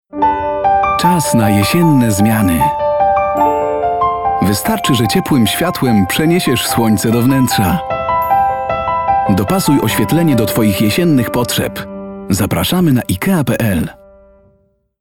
Mężczyzna 30-50 lat
Profesjonalny lektor. Polski aktor teatralny, filmowy i dubbingowy.
Nagranie lektorskie